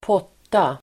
Ladda ner uttalet
Uttal: [²p'åt:a]